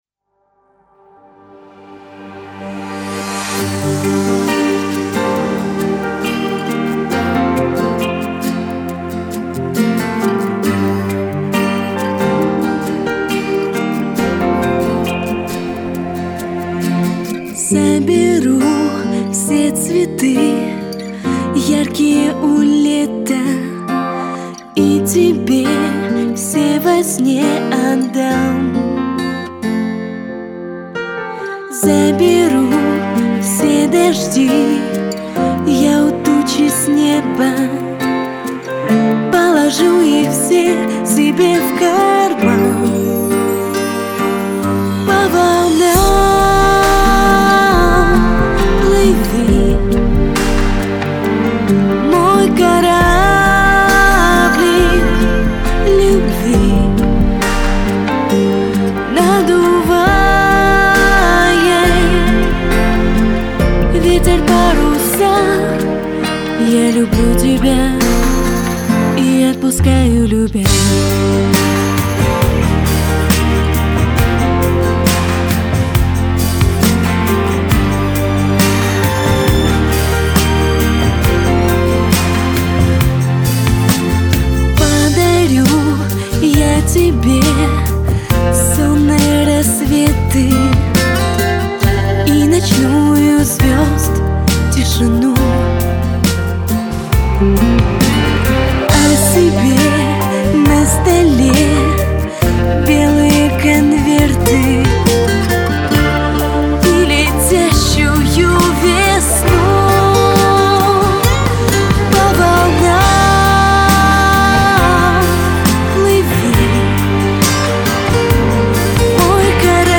krasivij_medljak___new_2011_.mp3